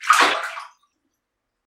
Fish jumping (sound effects)
Nature
A sound effect of a fish jumping in a pond....